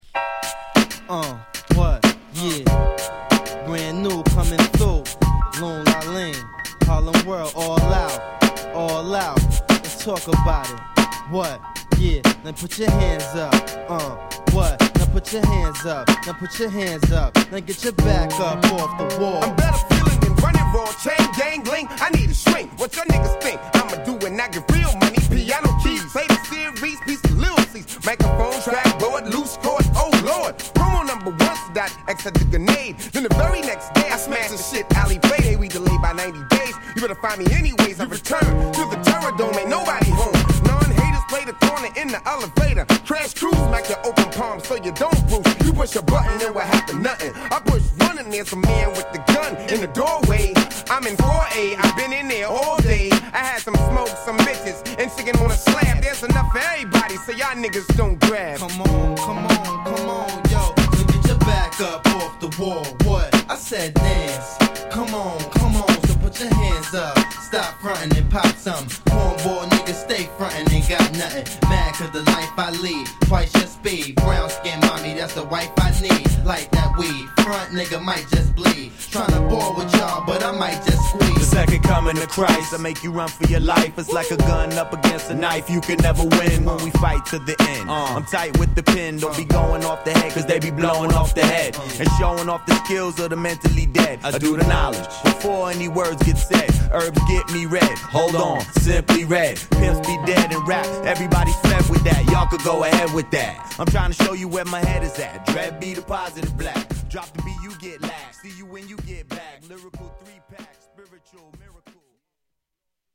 Street Version (with Intro)